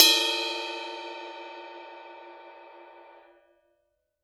Percussion
susCymb1-hit-bell_fff.wav